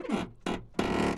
primer-in.wav